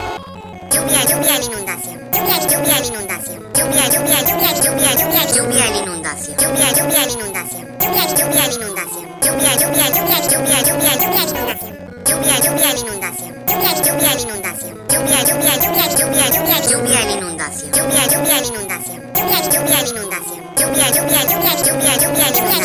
lluvia el inundacion ihd38dh3idje8djz8sje8dje8dj9ejd8j1e80fhefdh971ehe70dh703d - Botón de Efecto Sonoro